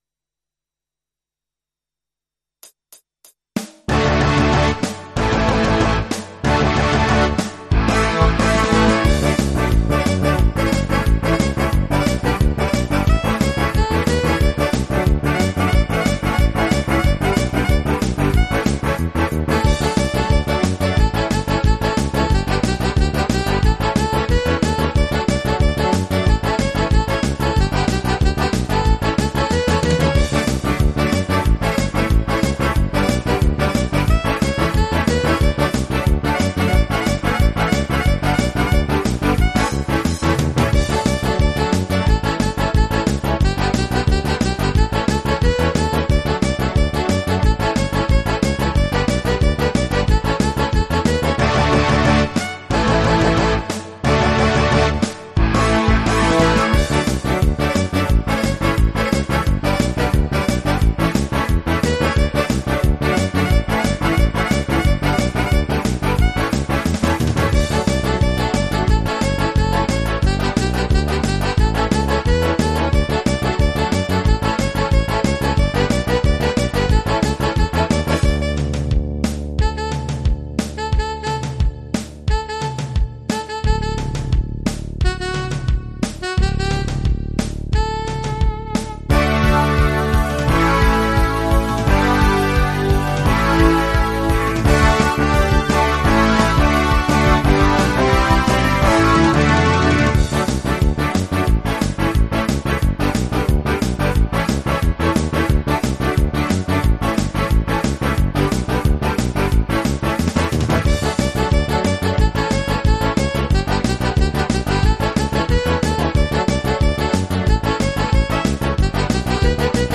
mehrspurige Instrumentalversion